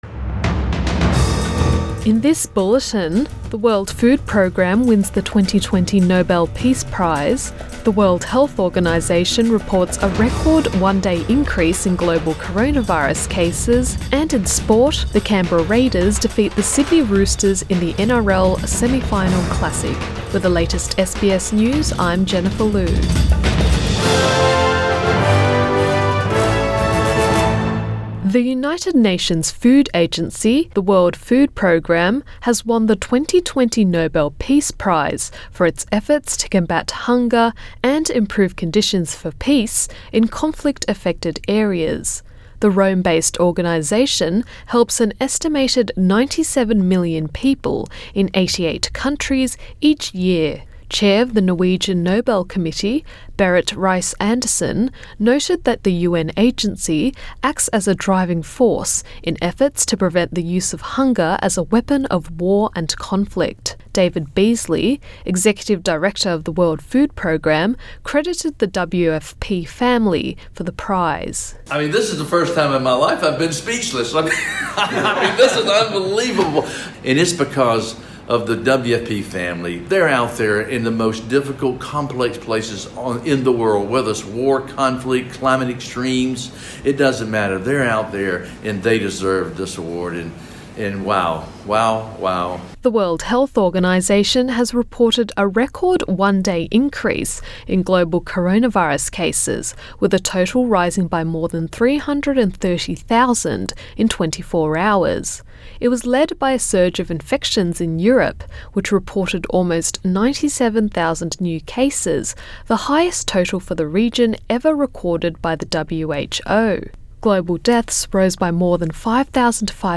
AM bulletin 10 October 2020